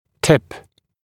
[tɪp][тип]наклонять; кончик, верхняя часть; ангуляция (о прописи брекетов)